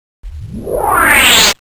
cartoon44.mp3